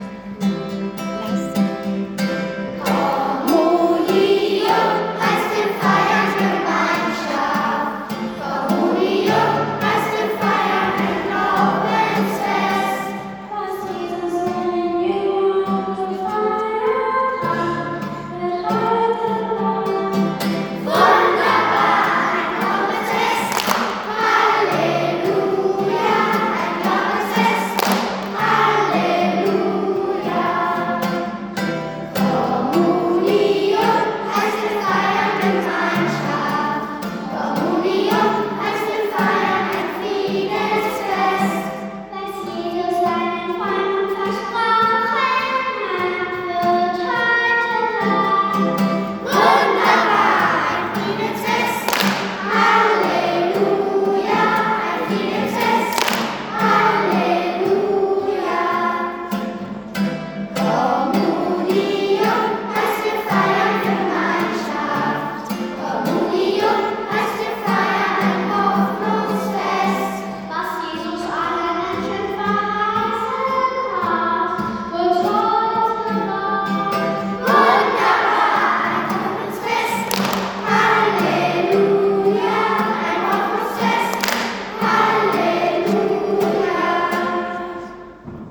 Erstkommunion am 10. Mai 2025
Danklied
ERSTKOMMUNION-DANKLIED.m4a